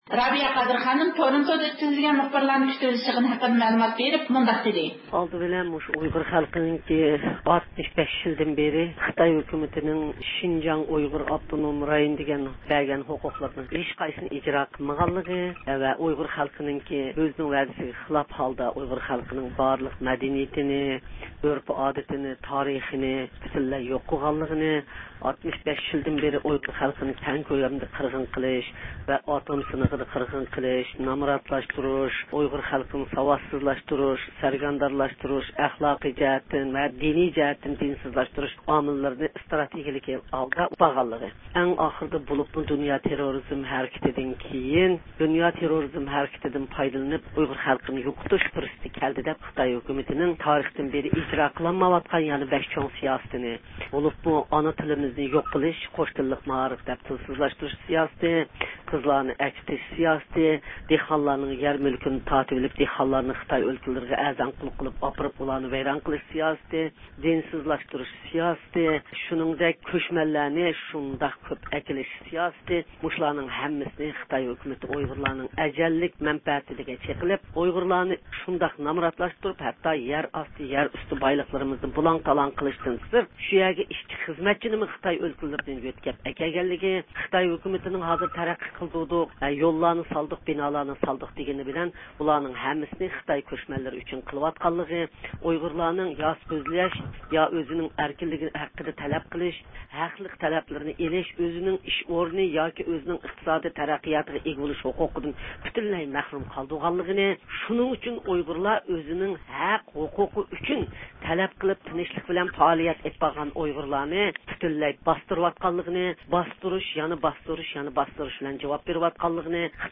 بۇ مۇناسىۋەت بىلەن رابىيە قادىر خانىم زىيارىتىمىزنى قوبۇل قىلىپ، مۇخبىرلارنى كۈتۈۋېلىش يىغىنىنىڭ ئەمەلىي ئەھۋالىنى ئاڭلاتتى.